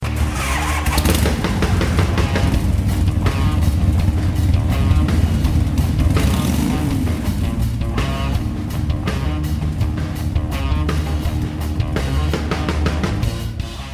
Hear the difference, listen to a motorcycle simulation below:
Motorcycle Engine Noise With and Without Plugfones
Motorcycle.mp3